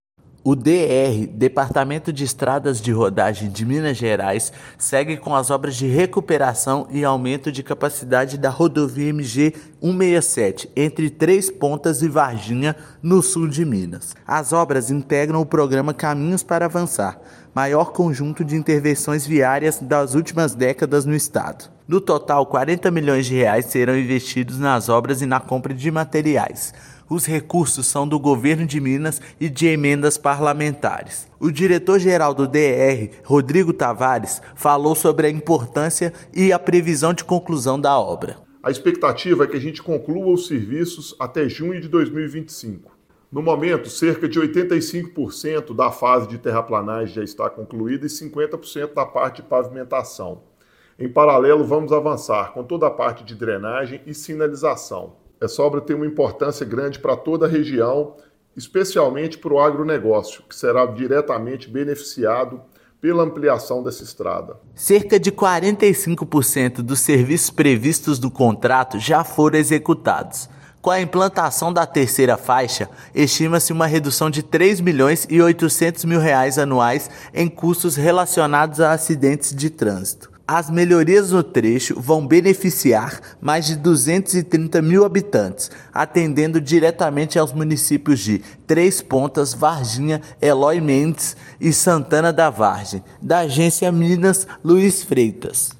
[RÁDIO] Obras na MG-167 avançam no Sul de Minas
Trecho liga Varginha a Três pontas e beneficia mais de 230 mil habitantes. Ouça matéria de rádio.